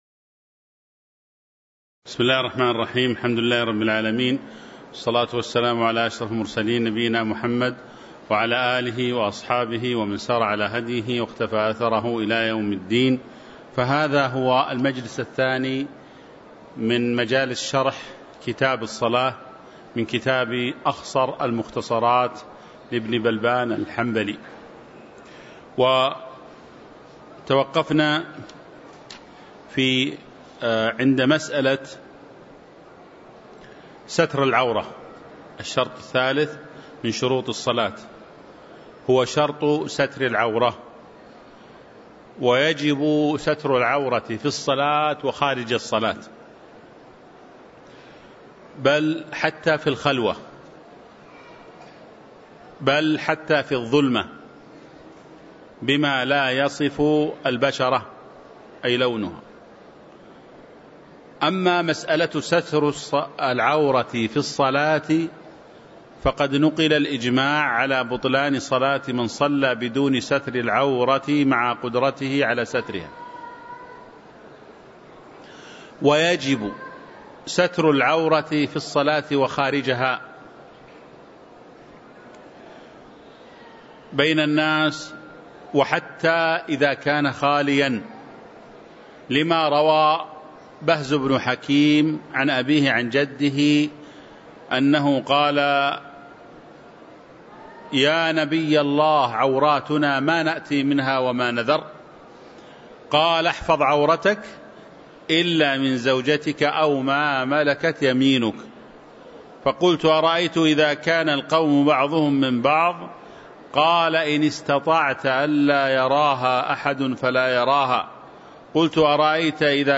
تاريخ النشر ٢١ جمادى الآخرة ١٤٤٥ هـ المكان: المسجد النبوي الشيخ